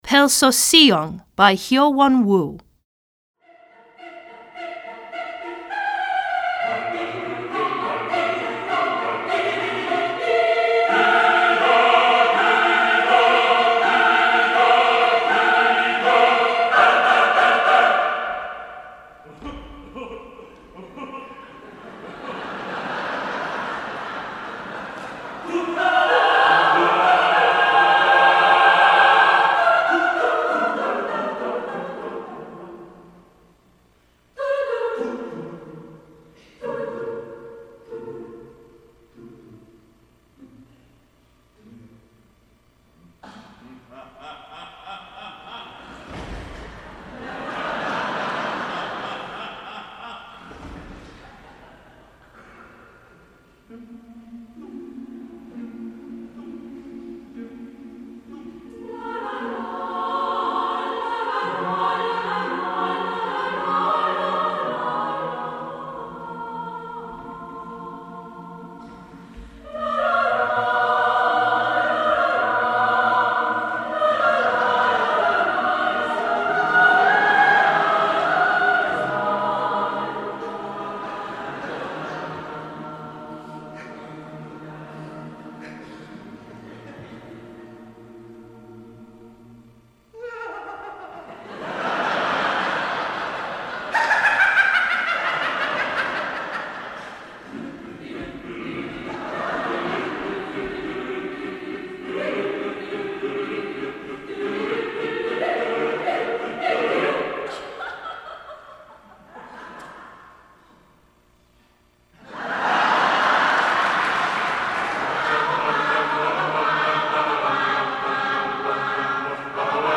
Choeur Mixte (SATB) et Percussions